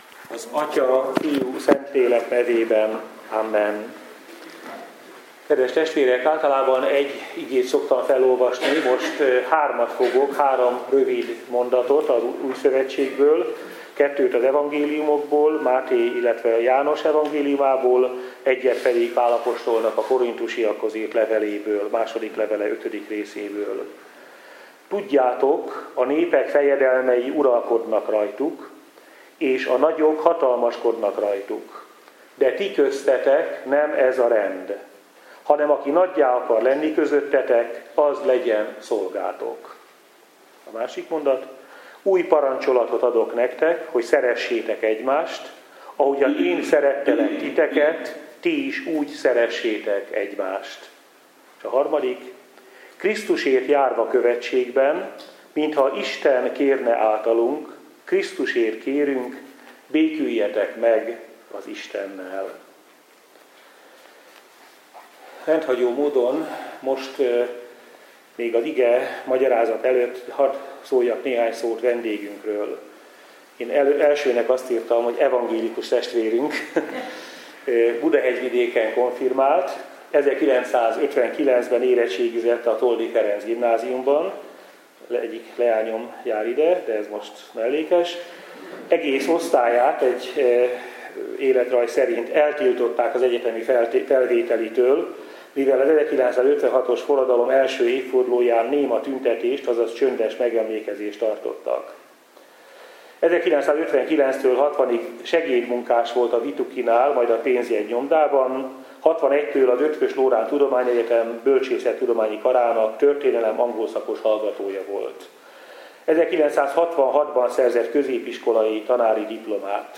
Budapest – A rendszerváltozás után szabadon választott első magyar kormány szilárdan hitt abban, hogy keresztyén alapon és a legjobb szándékok mentén rendezhetők a magyar nemzet és az környező népek között feszülő, évszázados konfliktusok. A kijózanító valóság keserűségét csak fokozta, amikor kiderült, hogy az Európai Unió teljességgel érzéketlen a kisebbségi problémák iránt – hangzott el a Budavári Evangélikus Szabadegyetem legutóbbi előadásán.